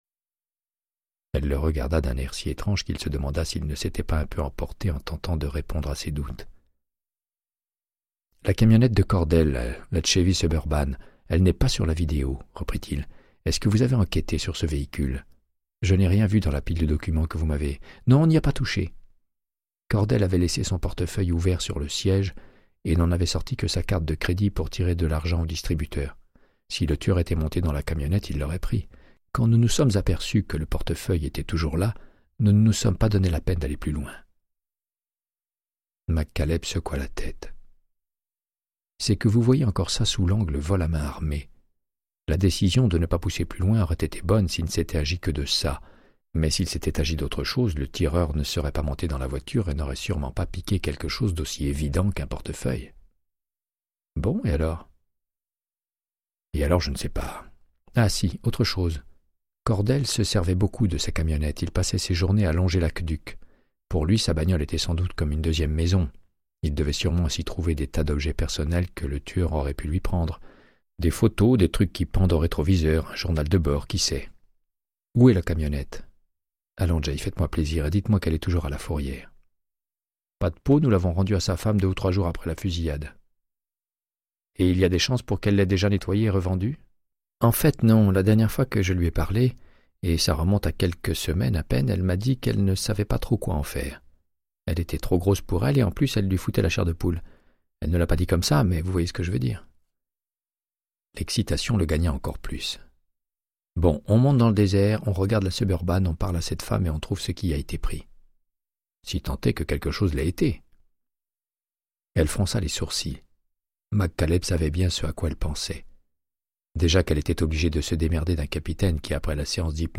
Audiobook = Créance de sang, de Michael Connellly - 80